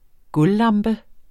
Udtale